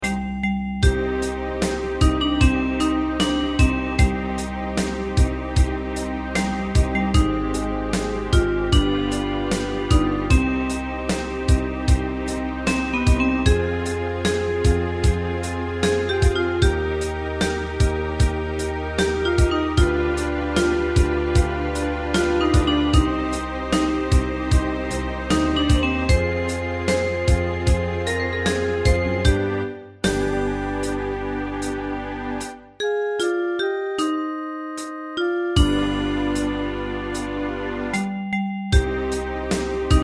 Unison musical score and practice for data.
Tags: Japanese , Kayokyoku Enka .